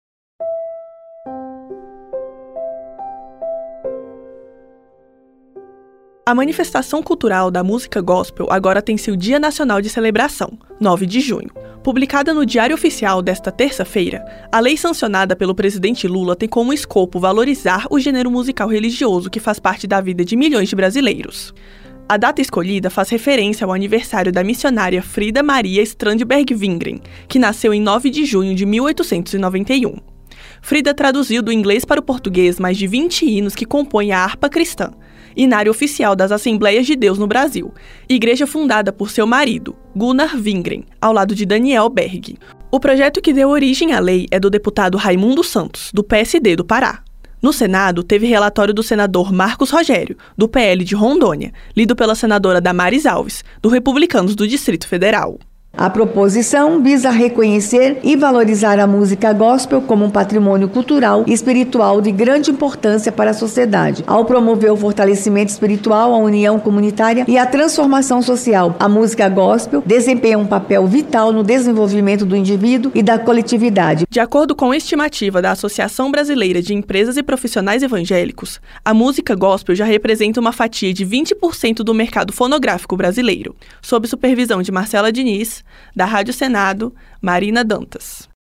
Senadora Damares Alves